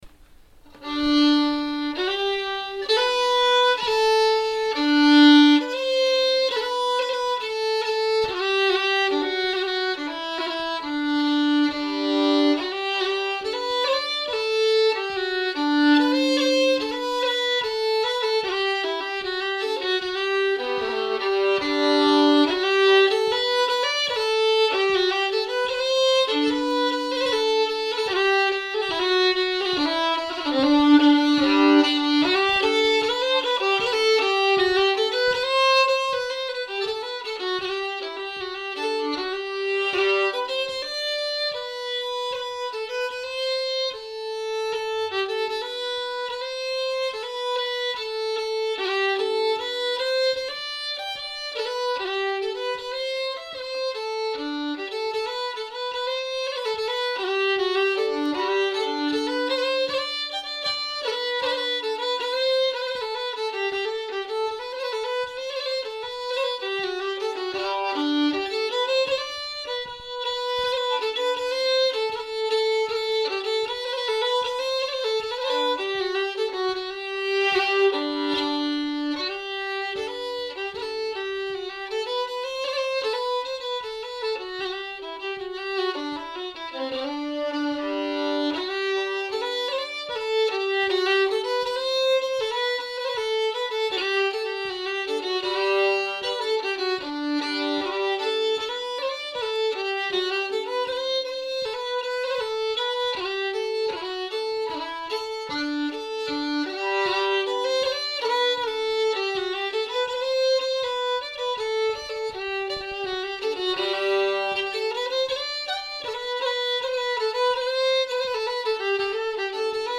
Slängpolska | Lustspel
Slängpolska fr Småland efter Olof Götlin.mp3